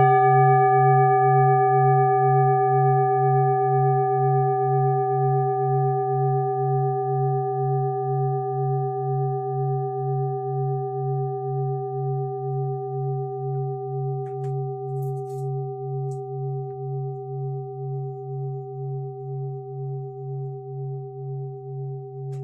Klangschalen-Typ: Bengalen
Klangschale 3 im Set 12
Klangschale N°3
(Aufgenommen mit dem Filzklöppel)
klangschale-set-12-3.wav